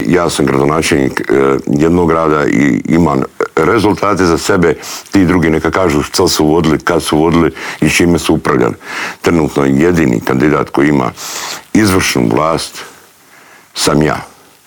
Miro Bulj izjavio je u studiju Media servisa da se kandidirao za predsjednika države jer mu je ‘‘puna kapa‘‘ gledati kako Hrvatska demografski tone i kako se vladajući odnose prema Hrvatskoj vojsci.